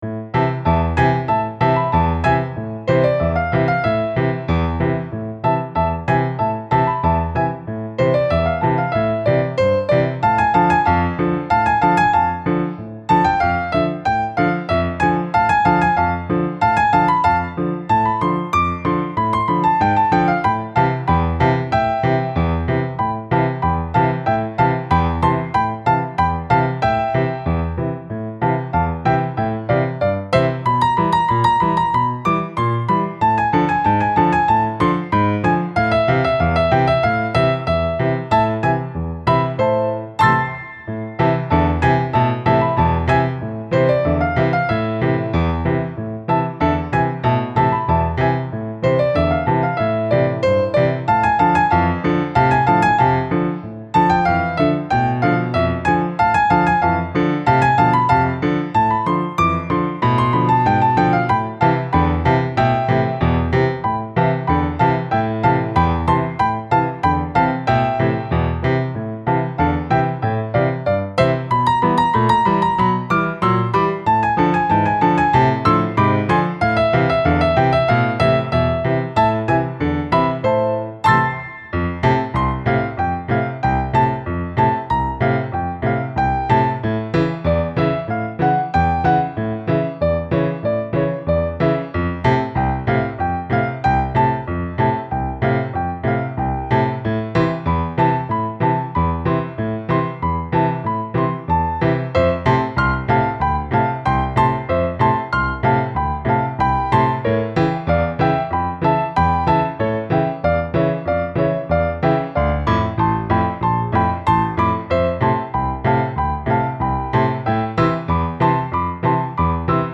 Style Style Folk
Mood Mood Bouncy, Mysterious
Featured Featured Piano
BPM BPM 94